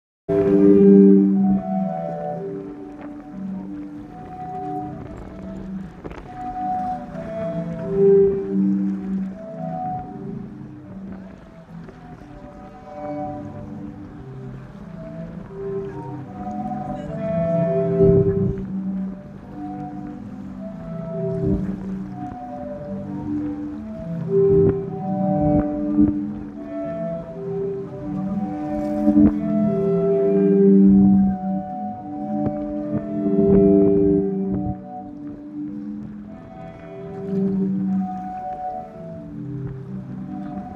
海风琴（Sea organ）是一架经过精心设计的海浪管风琴，白色石阶下暗藏35个大型风琴管，大海就是风箱。海水拍打和潮汐涨落会在风琴管中自动形成气压变化，美妙的乐声也随之产生。